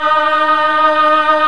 M CHOIR  2.1.wav